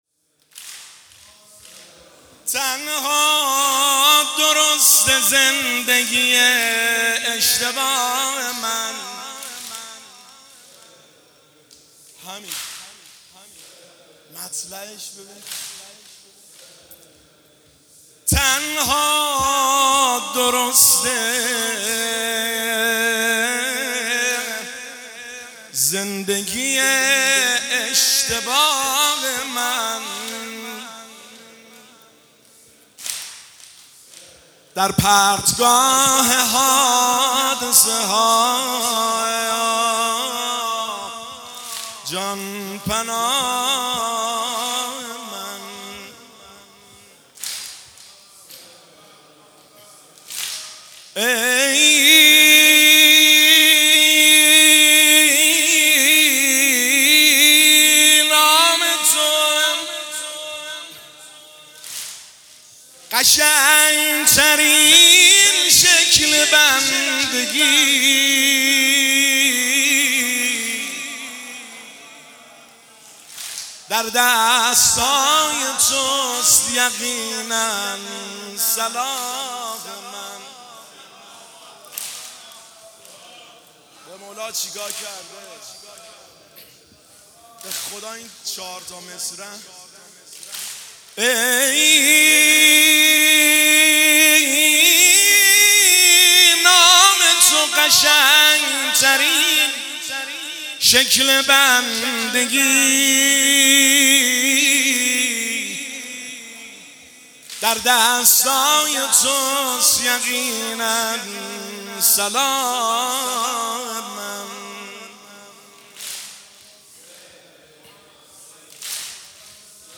هیئت بین الحرمین طهران
تنها-درست-زندگی-من-شعرخوانی.mp3